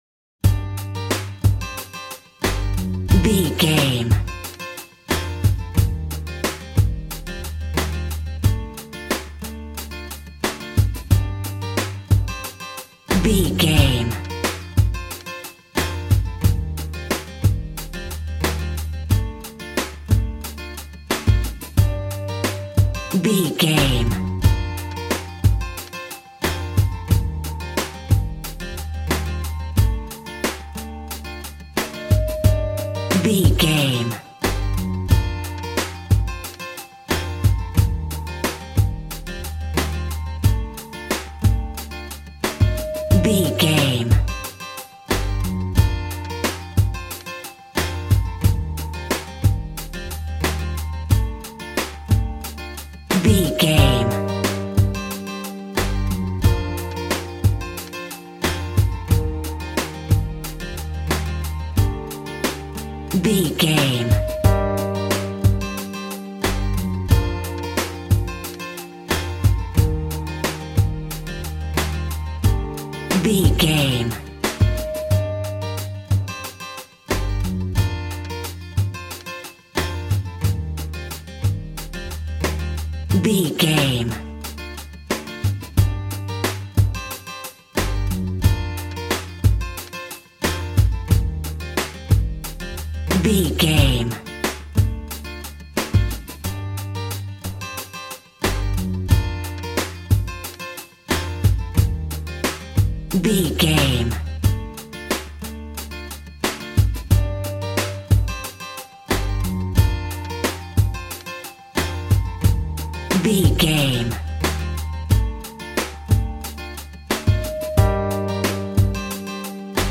Aeolian/Minor
Funk
electronic
drum machine
synths